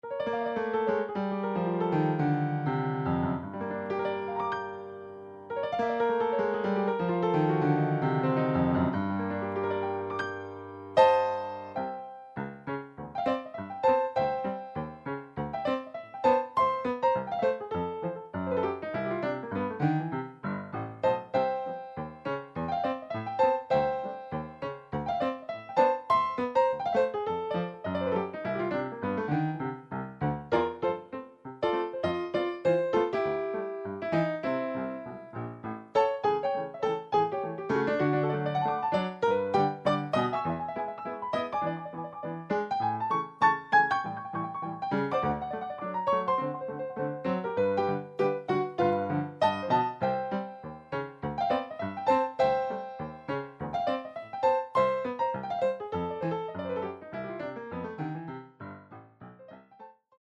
Style: Ragtime Piano